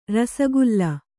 ♪ rasagulla